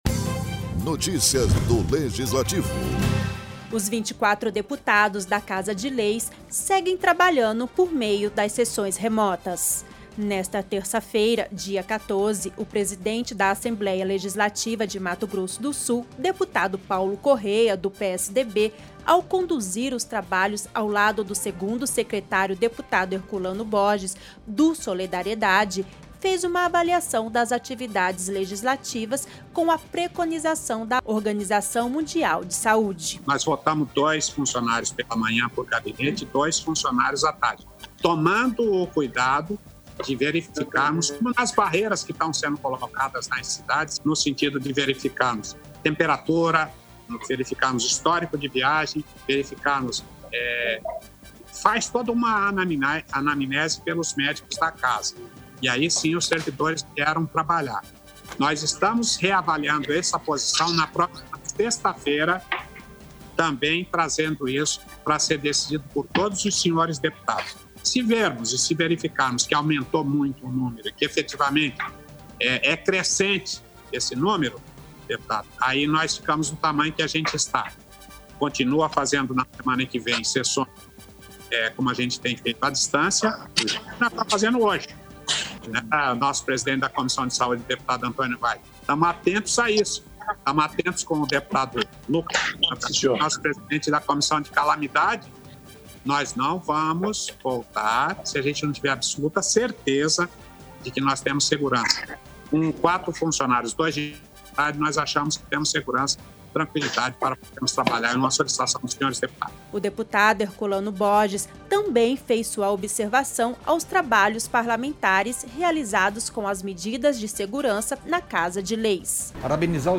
Download Locução e Produção